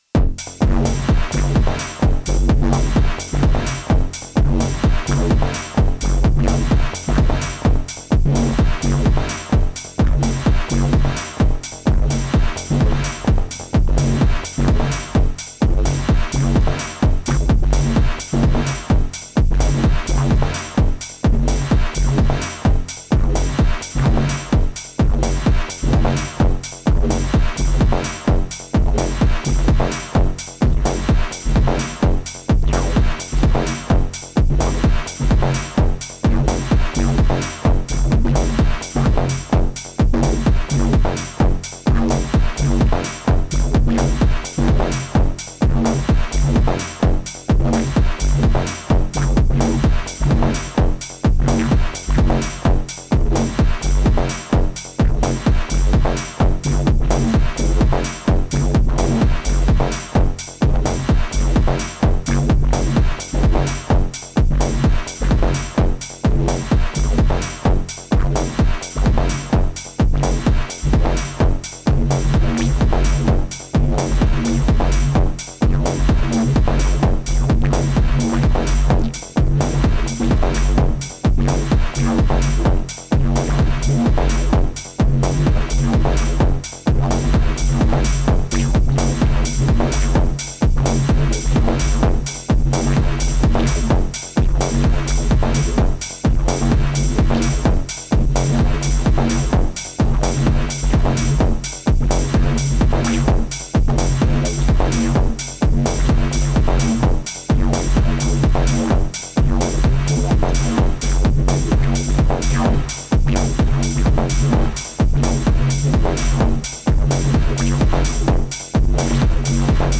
Techno System + Swarm